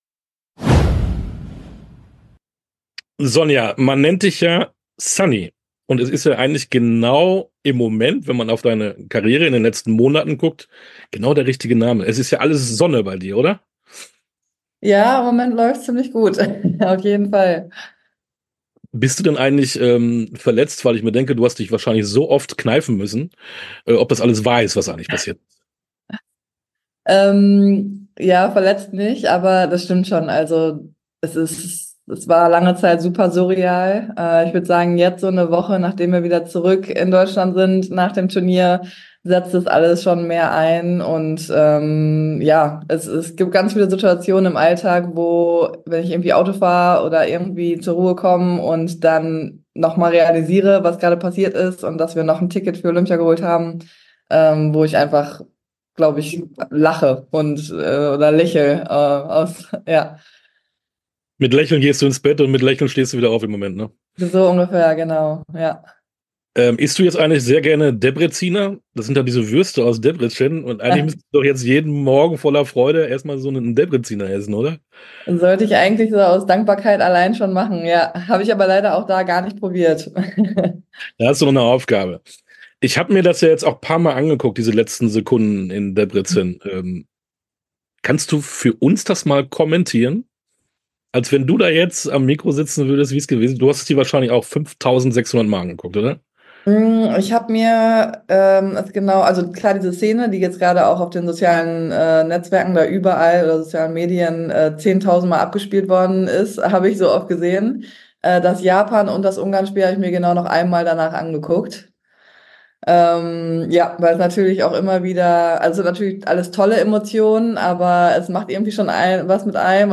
Sportstunde - Interview komplett